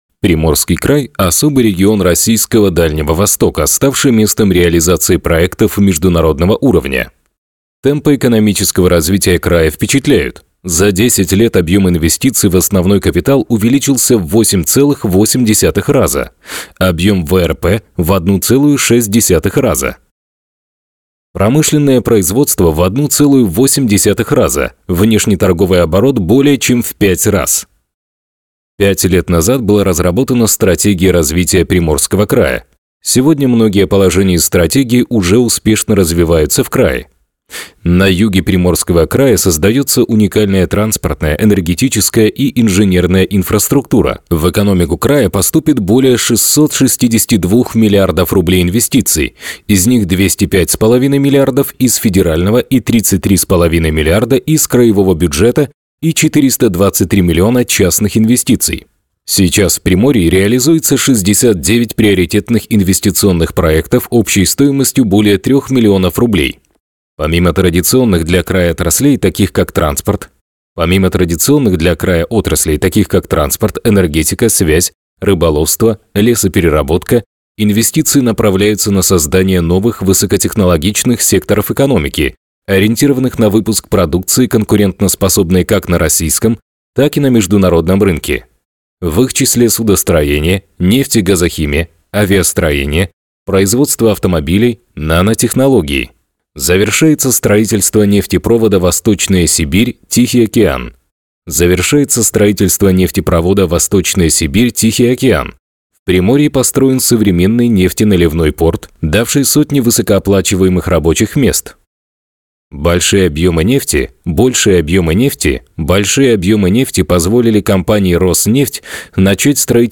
Native Russian voicetalent, narrator, presenter.
Sprechprobe: eLearning (Muttersprache):